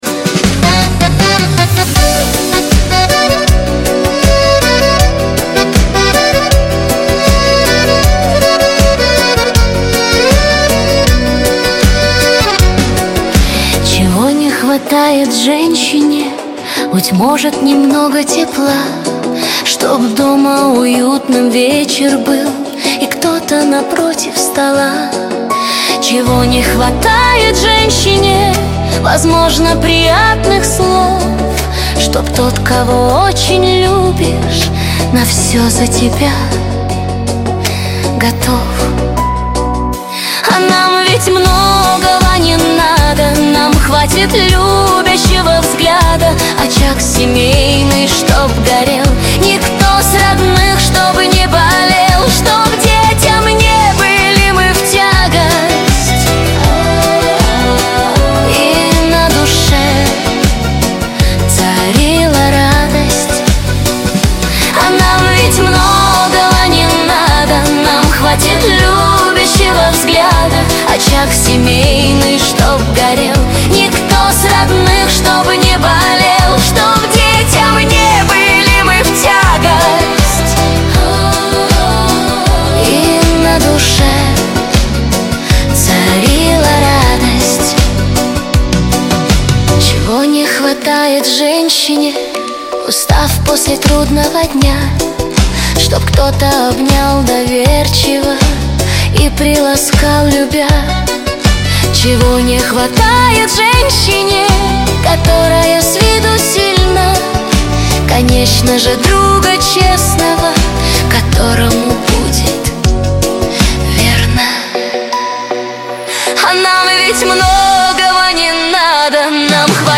Качество: 128 kbps, stereo
Нейросеть Песни 2025